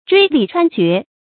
椎理穿掘 zhuī lǐ chuān jué